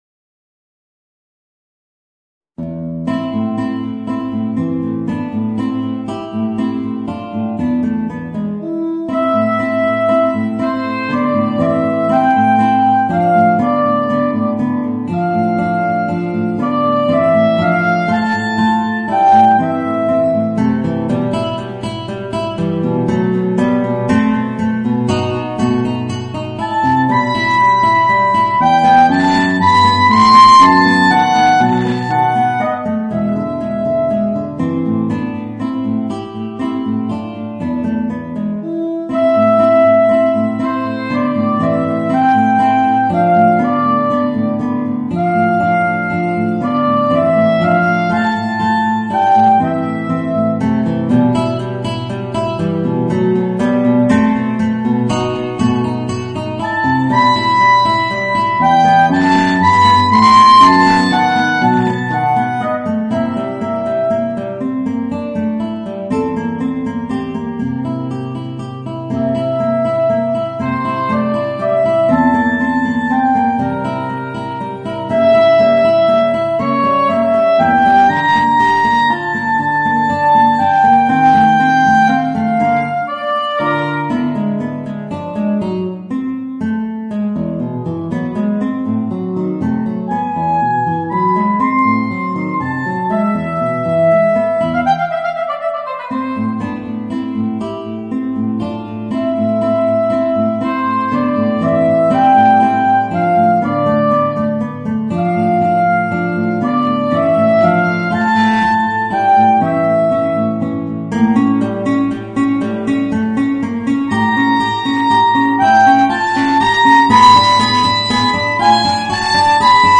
Voicing: Guitar and Soprano Saxophone